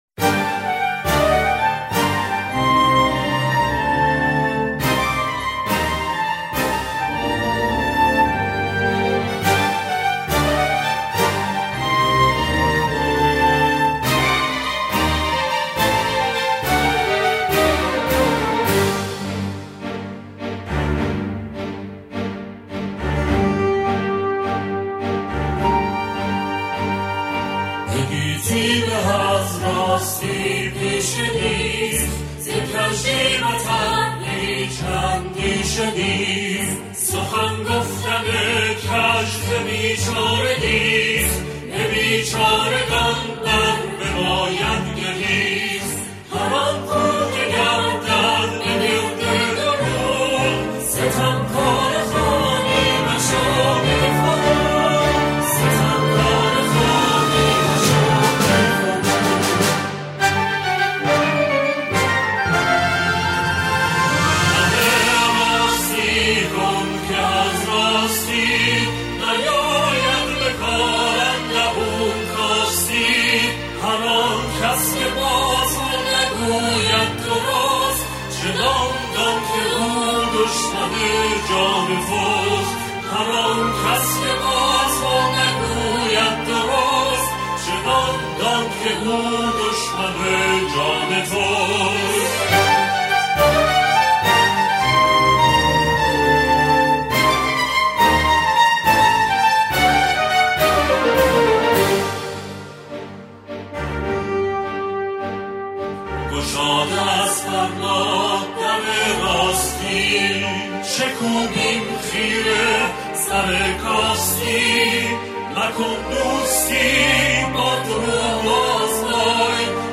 اعضای گروه کر